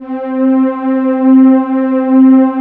20 PAD    -L.wav